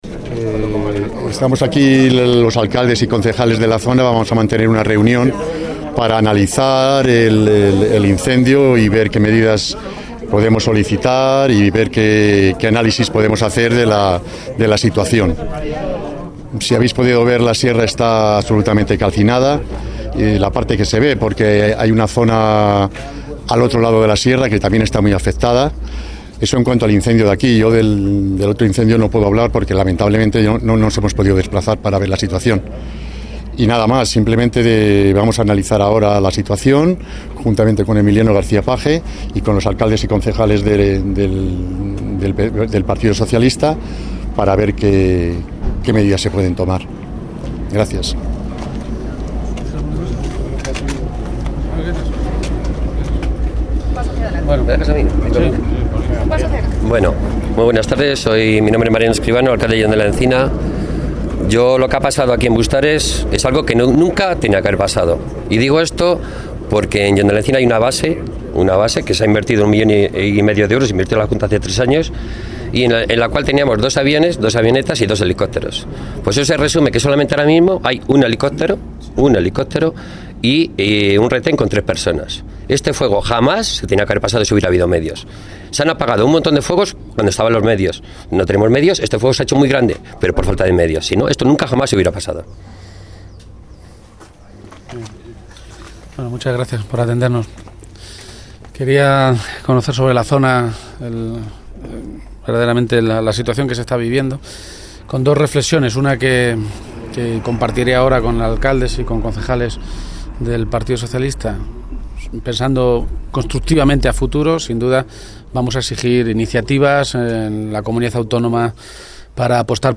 El secretario general del PSCM-PSOE, Emiliano García-Page, ha indicado esta tarde en Bustares que “exigiremos transparencia, más medios y ayudas” para los municipios afectados por los incendios que estos días han arrasado más de 3.000 hectáreas en la Sierra Norte de Guadalajara.